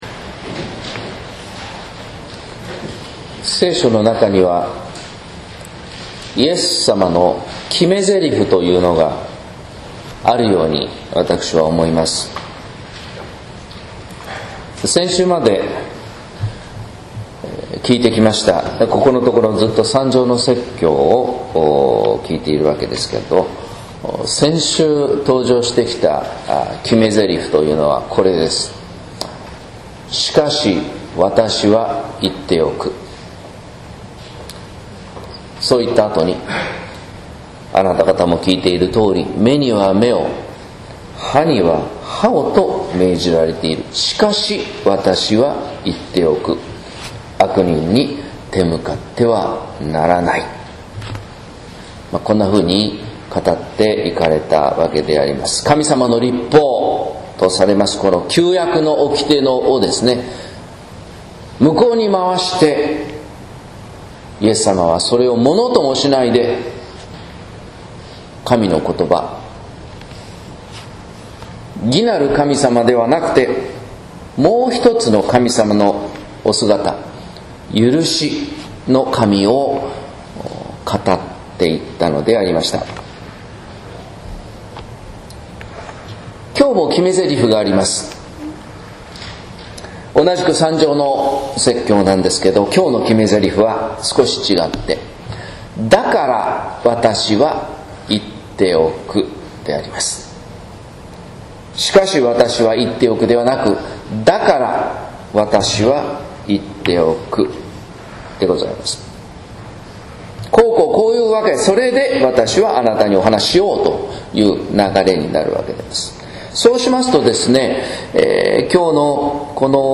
説教「義をください」（音声版） | 日本福音ルーテル市ヶ谷教会
説教「義をください」（音声版）